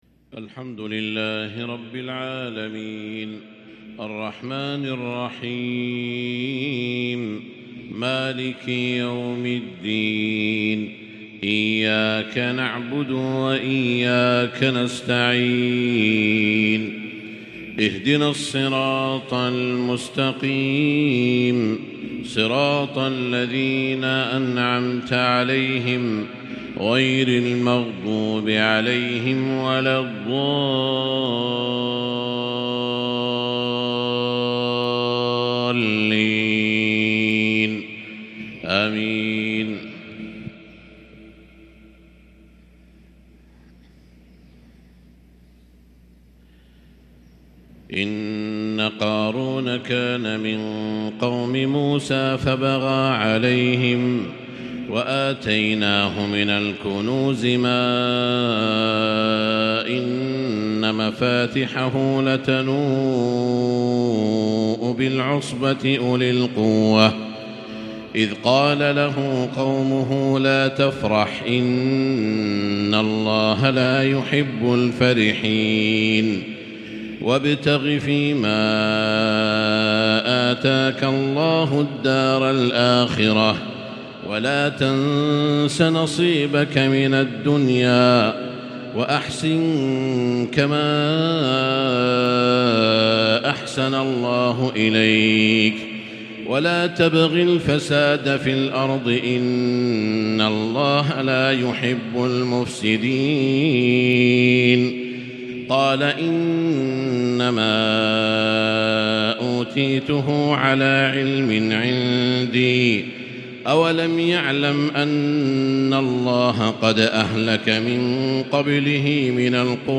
فجر الخميس 6 محرم 1444هـ آخر سورة القصص | Fajr prayer from Surat Al-Qasas 4-8-2022 > 1444 🕋 > الفروض - تلاوات الحرمين